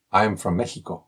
Q&A_02_response_w_accent.mp3